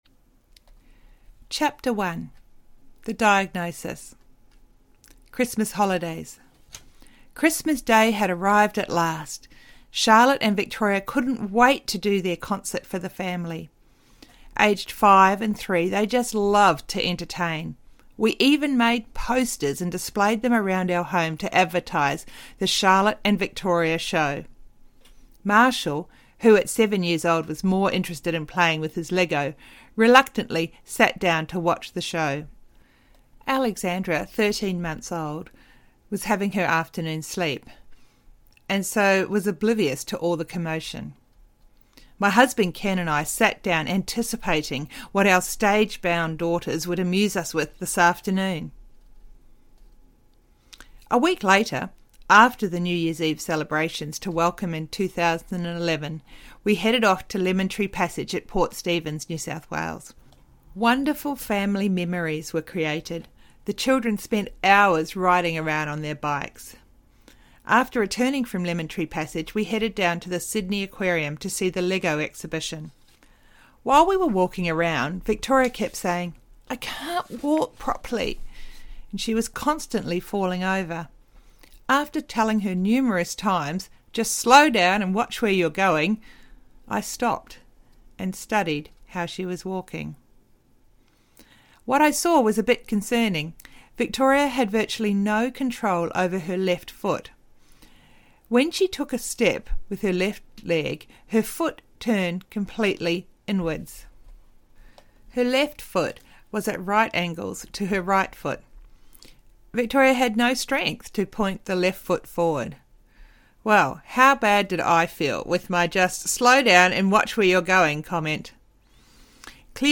Living With Victory Through Childhood Cancer Audiobook
7.9 Hrs. – Unabridged